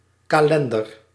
Ääntäminen
Belgique (Bruxelles): IPA: [a.ʒœ̃.da]